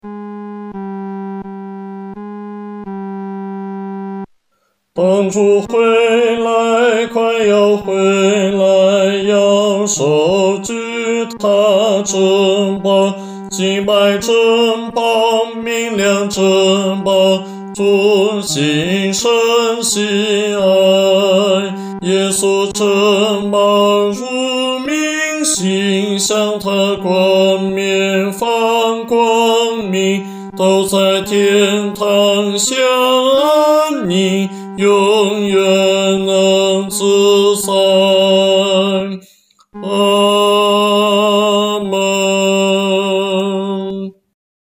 独唱（男高）